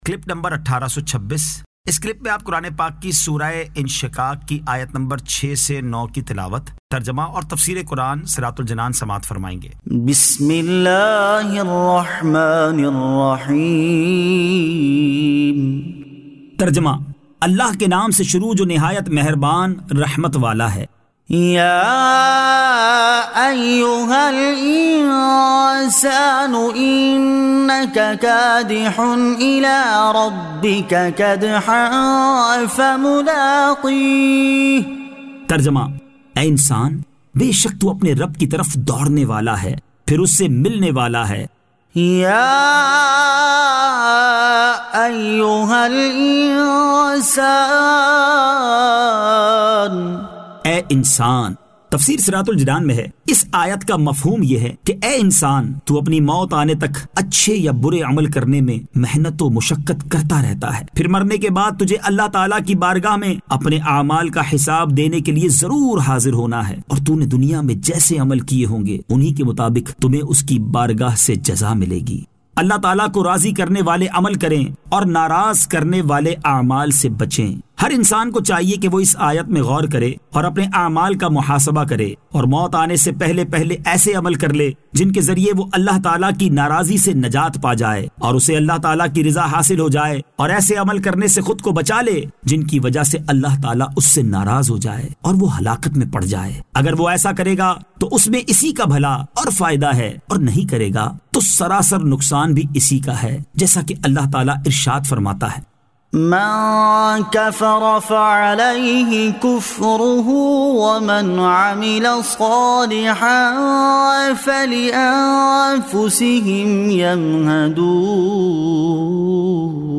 Surah Al-Inshiqaq 06 To 09 Tilawat , Tarjama , Tafseer